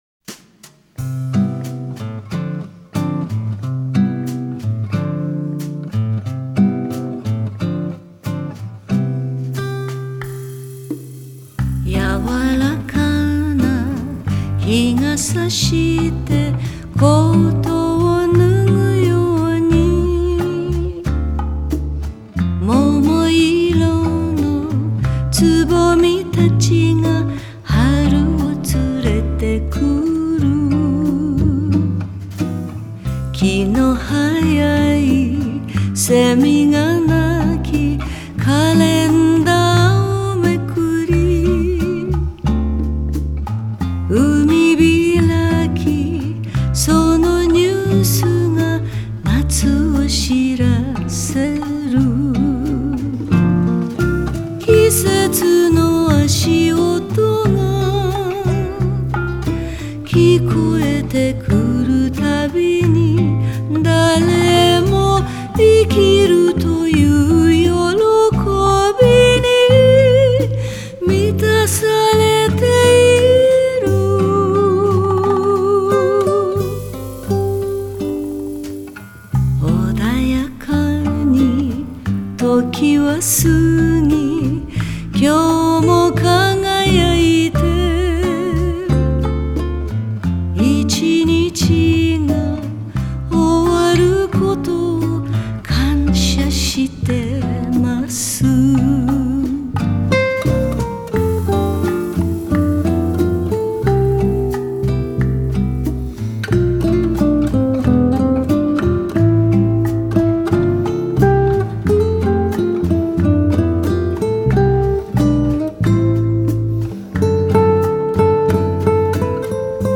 ジャンル: Jazz